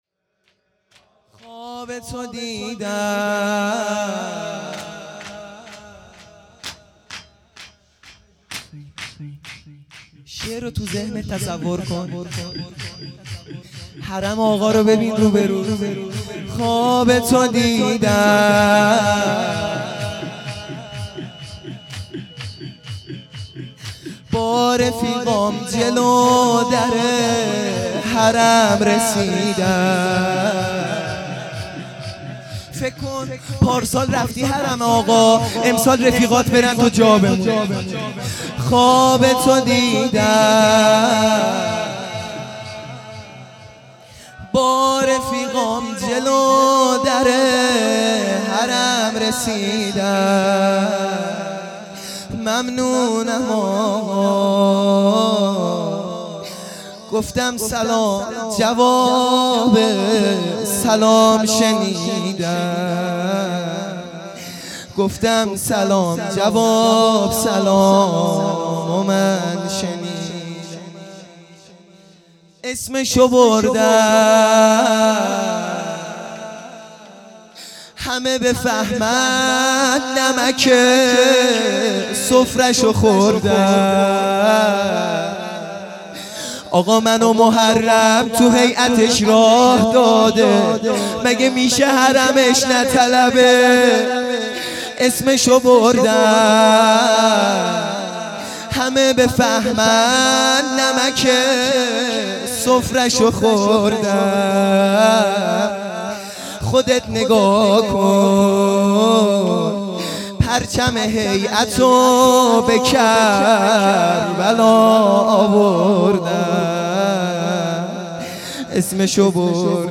شور | خوابتو دیدم